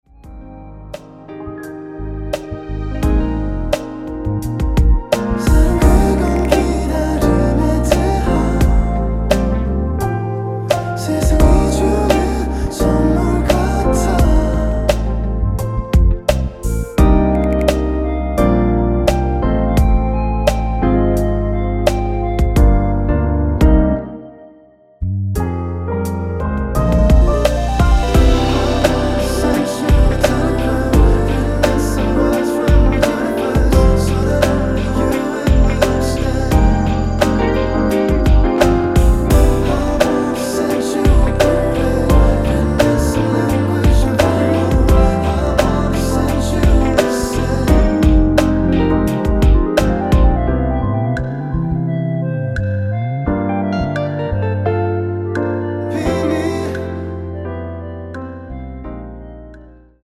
원키 멜로디와 코러스 포함된 MR입니다.(미리듣기 확인)
Eb
앞부분30초, 뒷부분30초씩 편집해서 올려 드리고 있습니다.
중간에 음이 끈어지고 다시 나오는 이유는